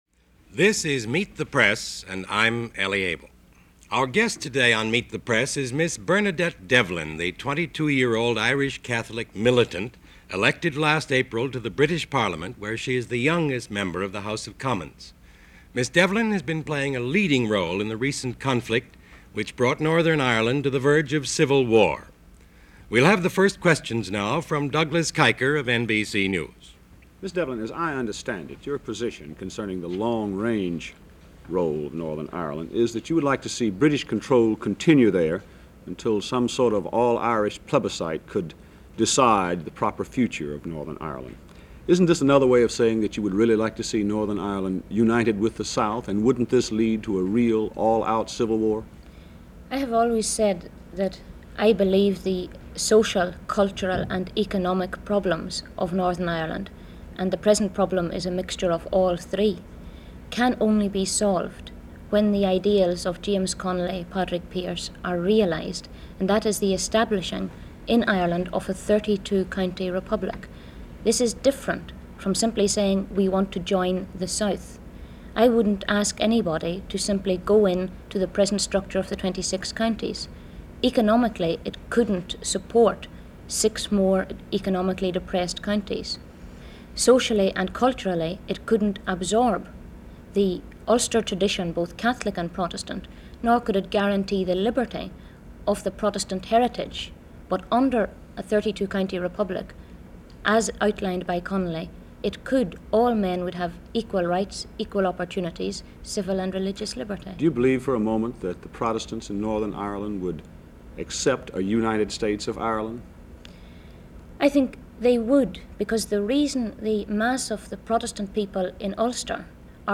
A few words from Bernadette Devlin - Meet The Press - August 24, 1969 - Interview with Devlin, the youngest member of Parliament and from Northern Ireland.